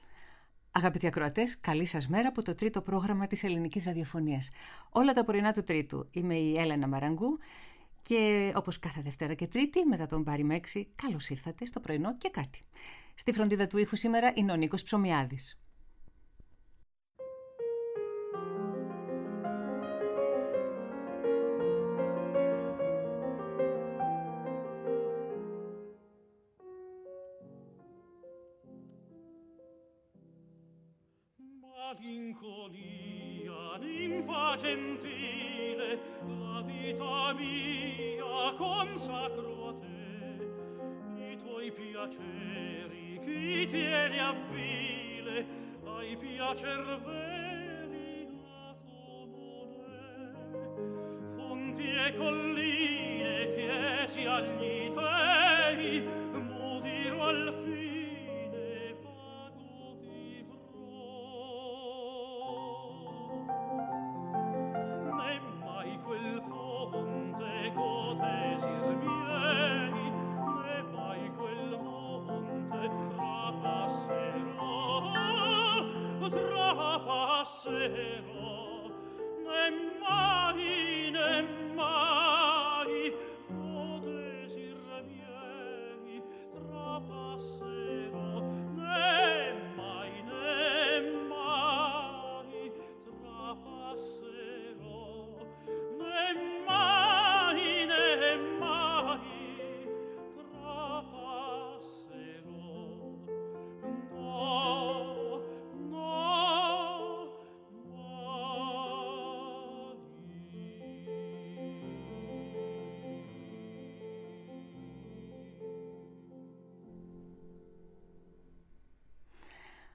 Ερμηνείες από όλες τις εποχές και μερικές ανατροπές συνοδεύουν τις μικρές και μεγάλες εικόνες της ημέρας.